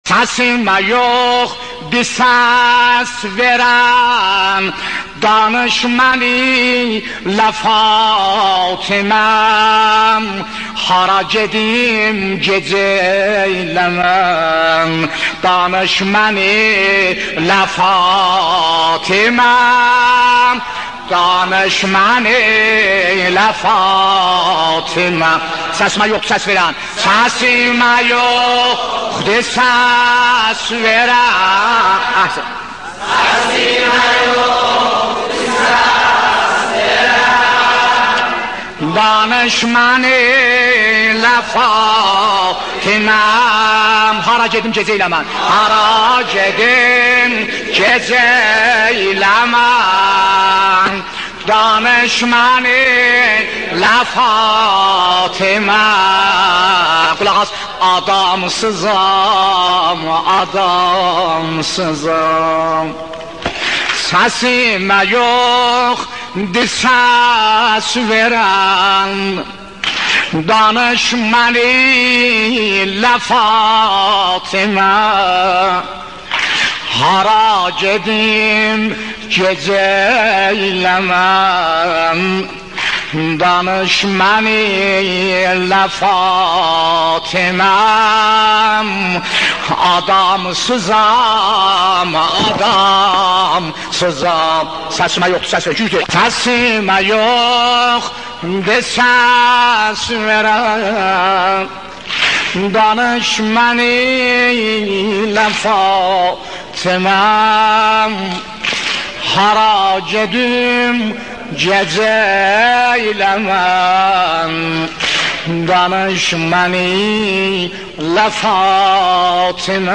شهادت حضرت زهرا ایام فاطمیه مداحی ترکی بخش اول